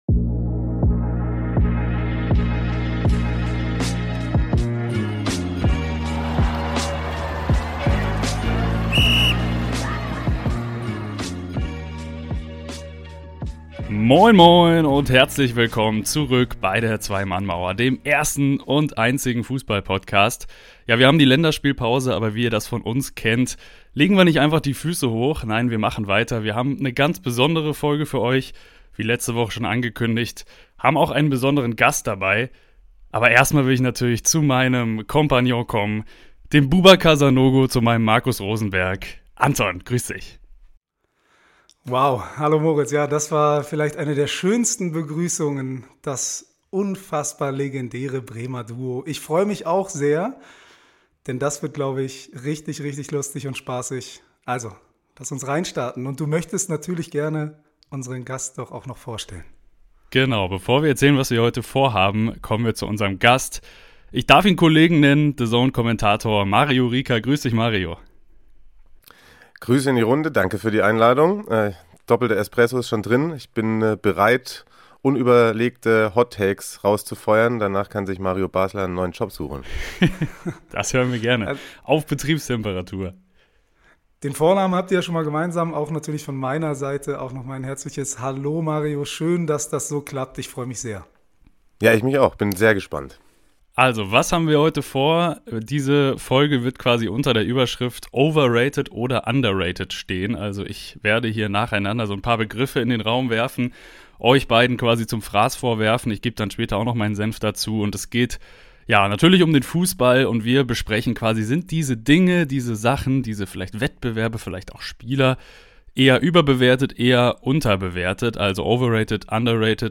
Zu dritt diskutieren die Jungs, welche Dinge aus der Fußballwelt über- oder eher unterschätzt werden. Die Nations League, Stadionbier oder Leroy Sané?